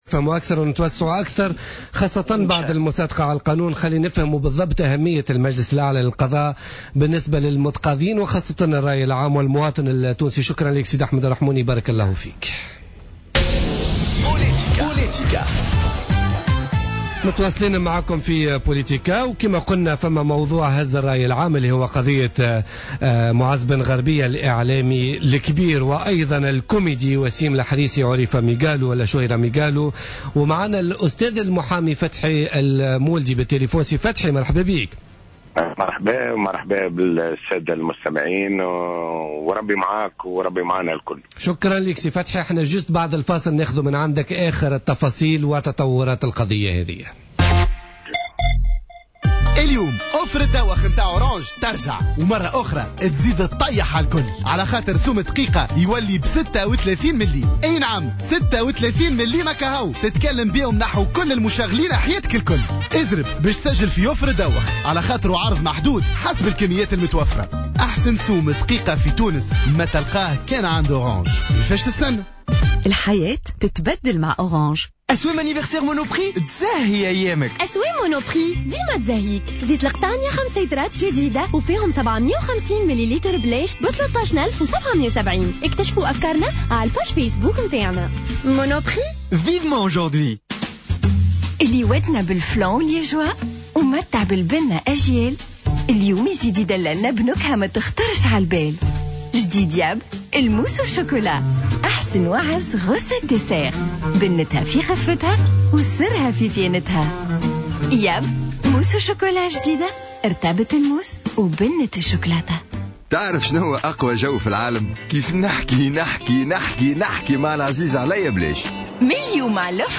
في مداخلة له في برنامج بوليتيكا